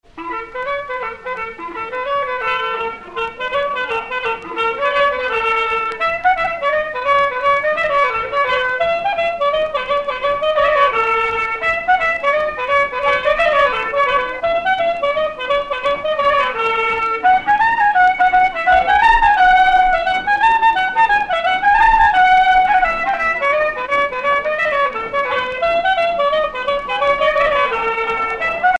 danse : fisel (bretagne)
Sonneurs de clarinette en Bretagne
Pièce musicale éditée